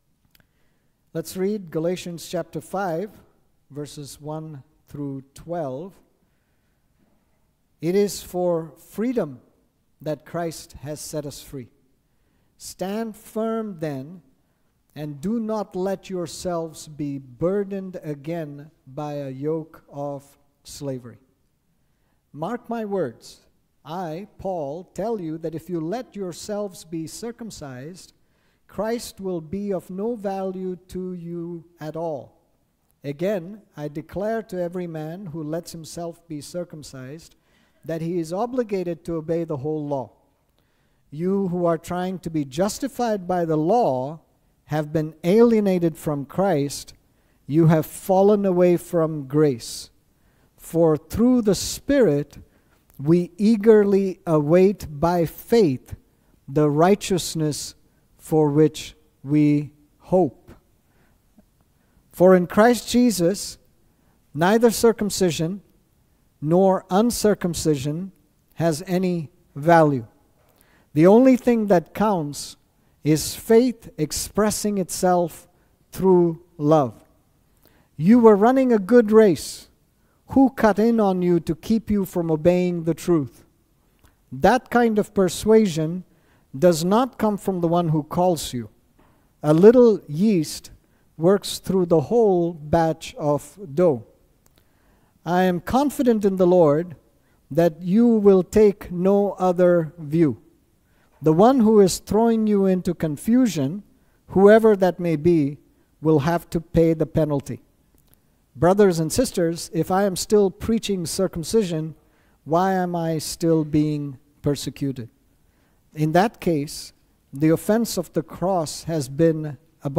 This sermon